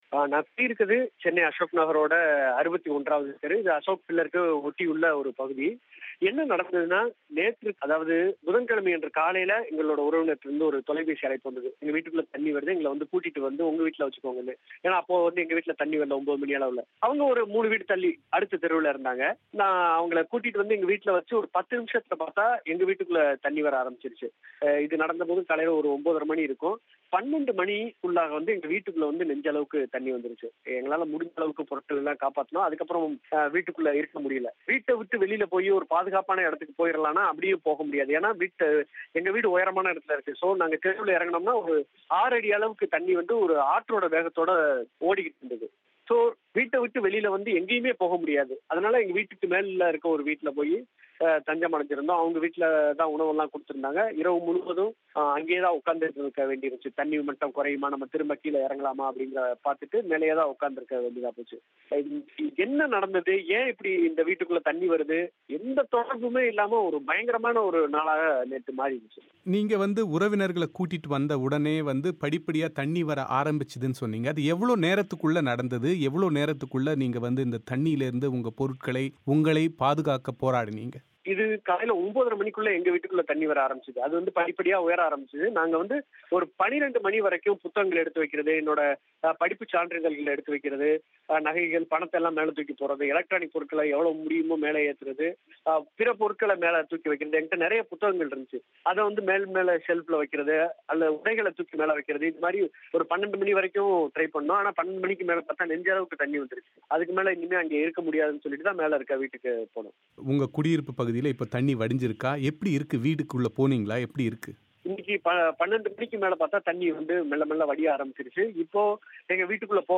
சென்னை வெள்ளத்திலிருந்து தப்பியதெப்படி? செய்தியாளரின் நேரடி அனுபவம்